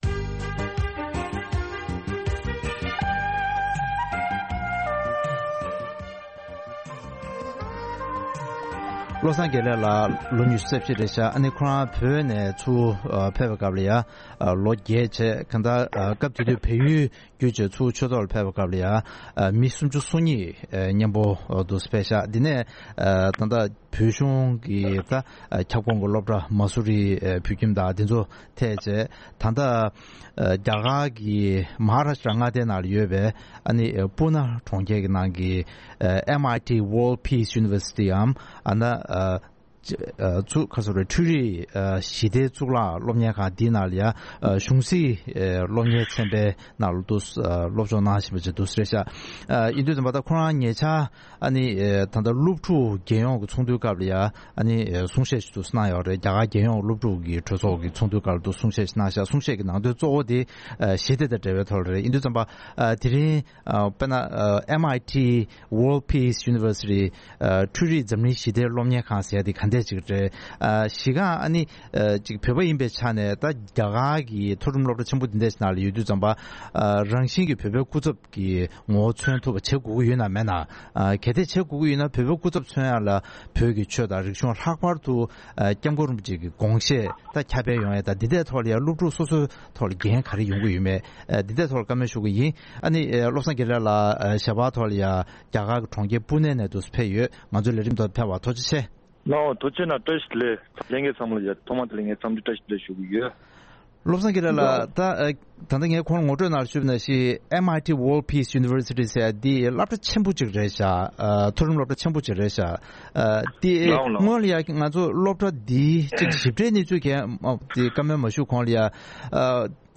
ཐེངས་འདིའི་གནད་དོན་གླེང་མོལ་གྱི་ལེ་ཚན་ནང་།